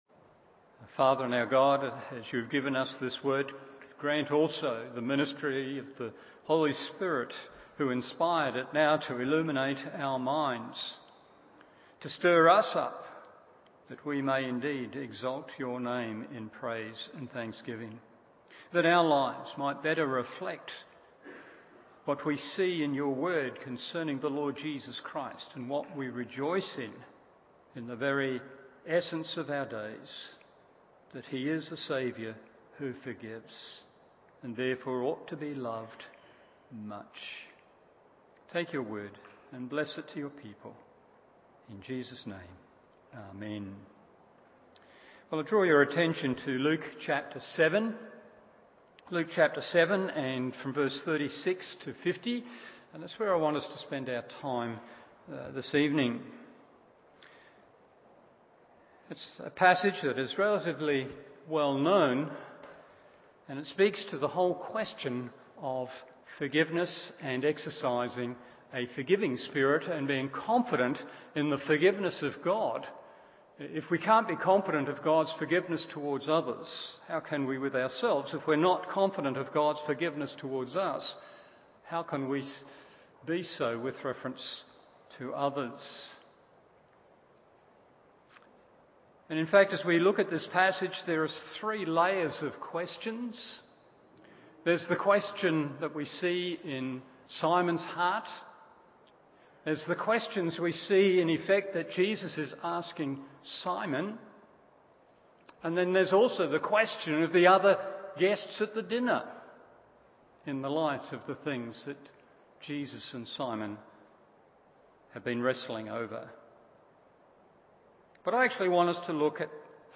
Morning Service Luke 7:36-50 1. How Could She? 2. How Could She Not? 3. Why Didn’t You?